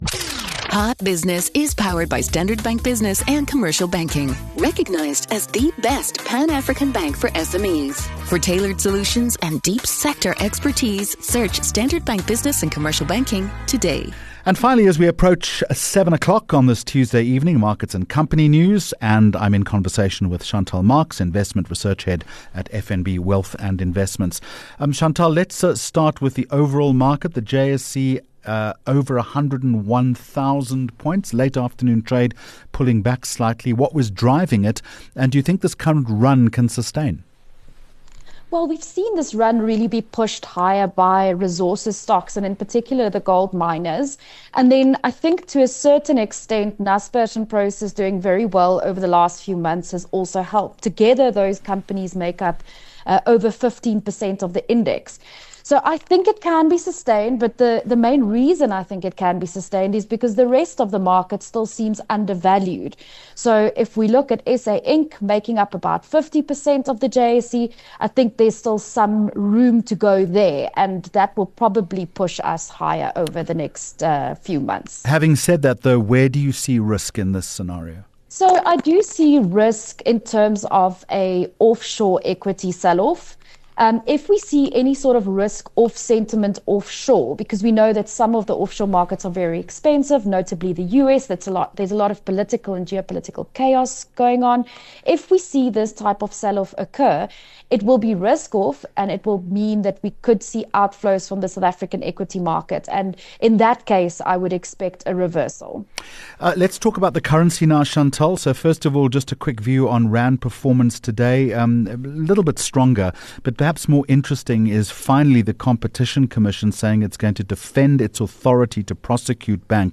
12 Aug Hot Business interview